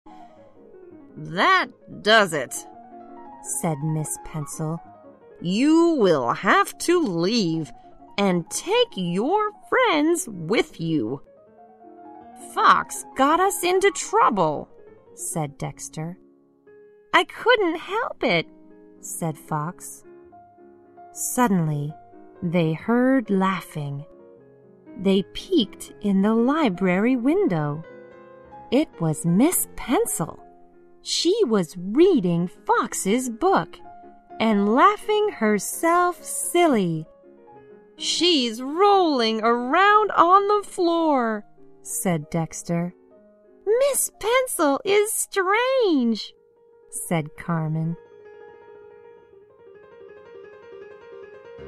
在线英语听力室小狐外传 第21期:铅笔老师的听力文件下载,《小狐外传》是双语有声读物下面的子栏目，非常适合英语学习爱好者进行细心品读。故事内容讲述了一个小男生在学校、家庭里的各种角色转换以及生活中的趣事。